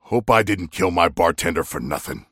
Abrams voice line - Hope I didn't kill my bartender for nothing.